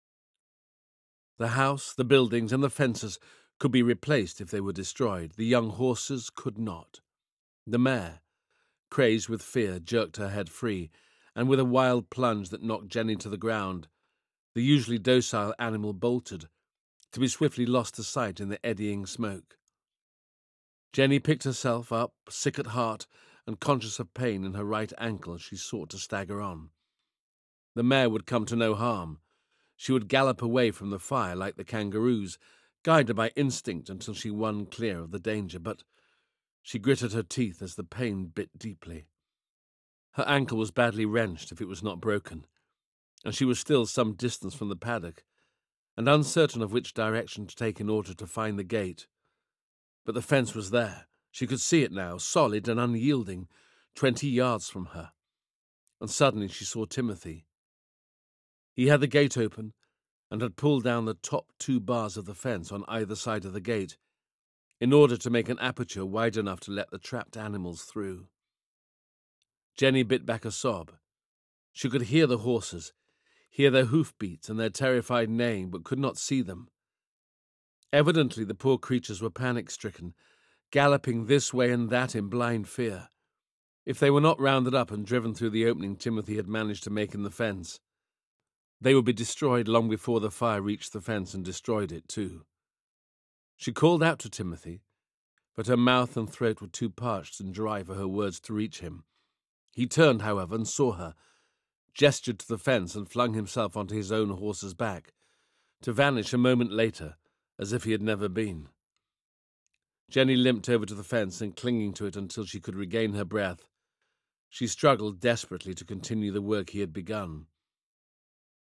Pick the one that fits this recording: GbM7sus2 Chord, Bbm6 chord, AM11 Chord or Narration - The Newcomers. Narration - The Newcomers